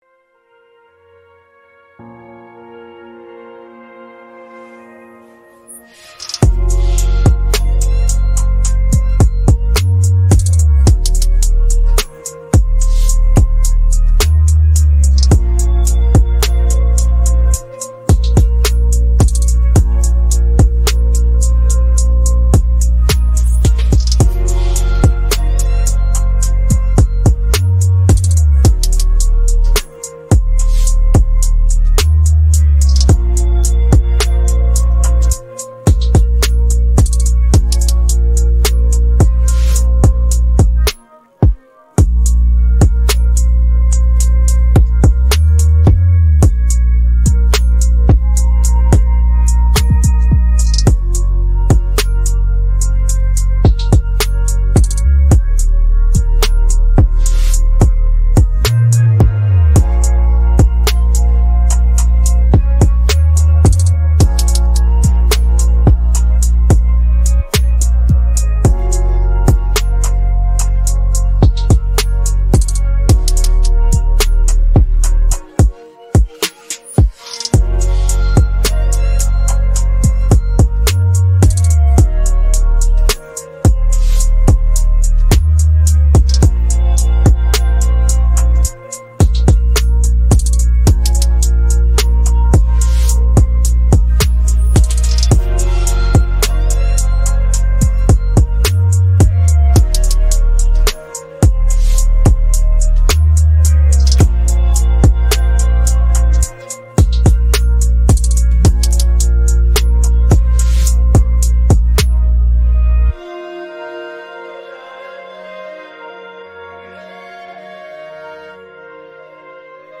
українське караоке 622